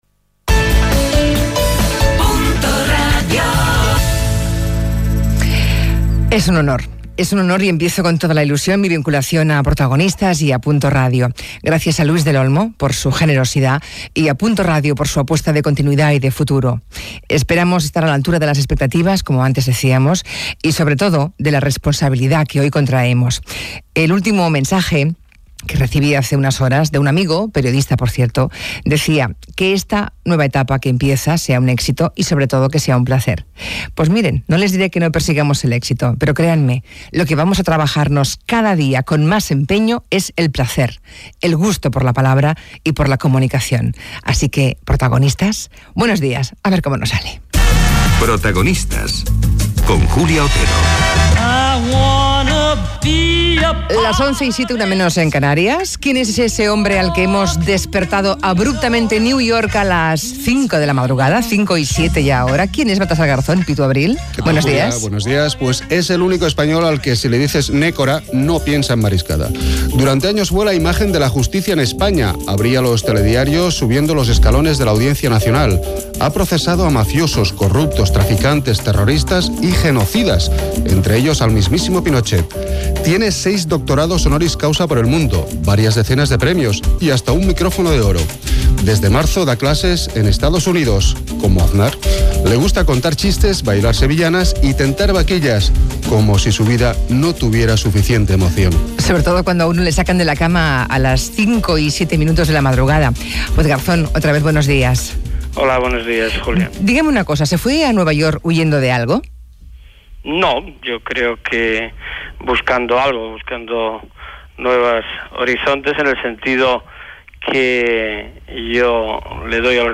Indicatiu de l'emissora. Presentació, perfil del jutge Baltasar Garzón i entrevista.
Info-entreteniment
FM